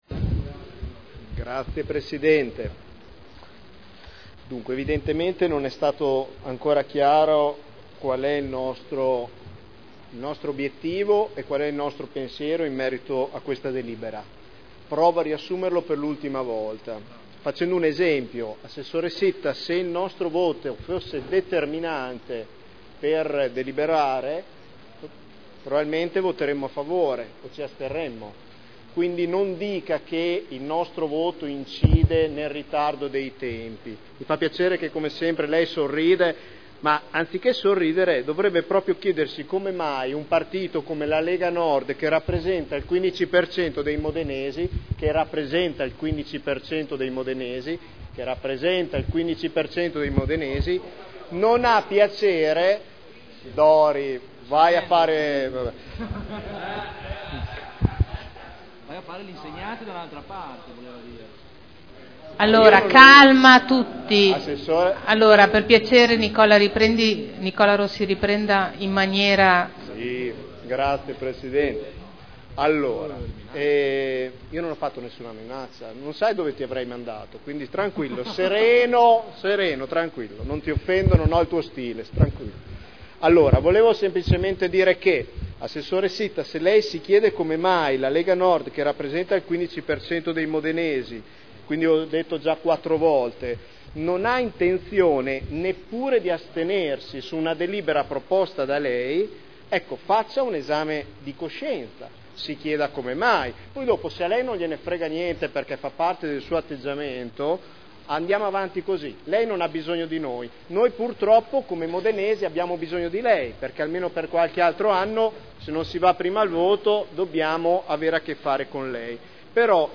Nicola Rossi — Sito Audio Consiglio Comunale
Seduta del 13/12/2010 Deliberazione: Variante al P.O.C.-RUE – AREA in via Emilia Ovest Z.E. 1481-1502 – Adozione Dichiarazioni di voto